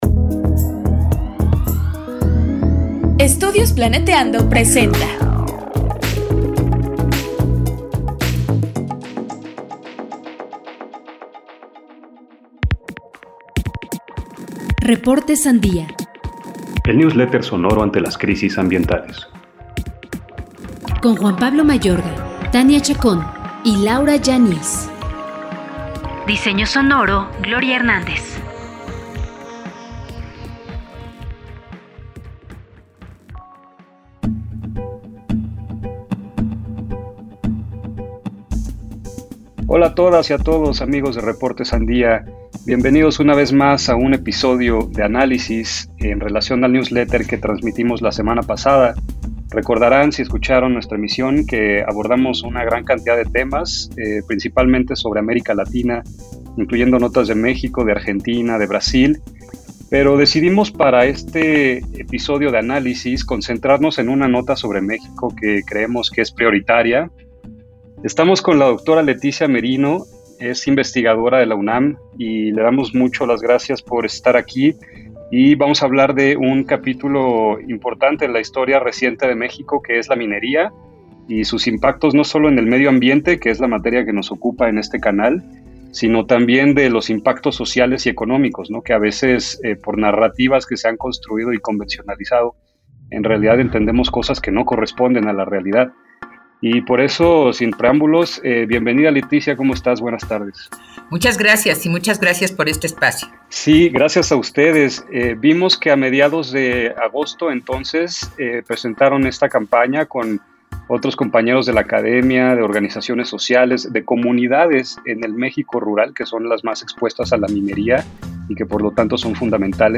RS-ENTREVISTA-14-mezcla.mp3